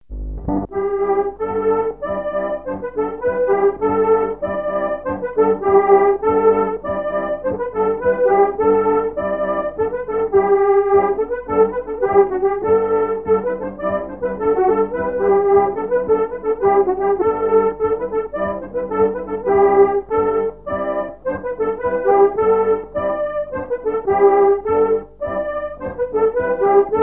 Chants brefs - A danser
polka piquée
Pièce musicale inédite